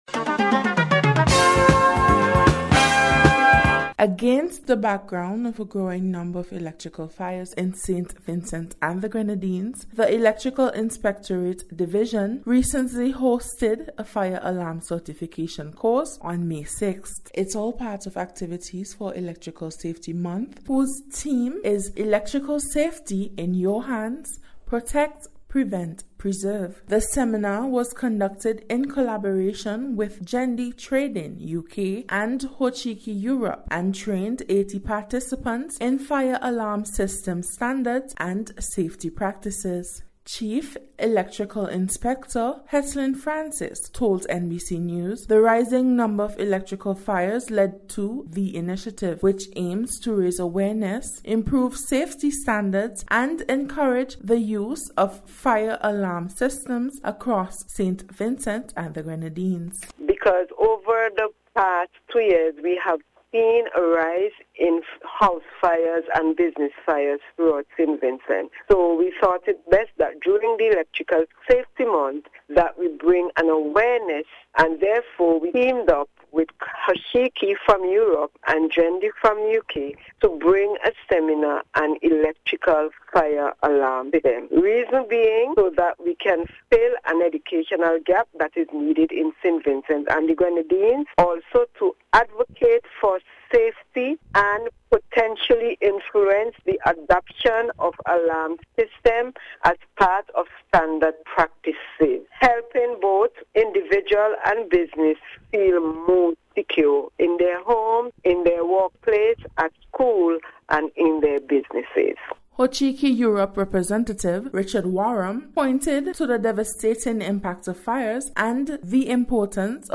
In this special report, we look at a recent certification course to raise awareness and promote international safety standards during Electrical Safety Month.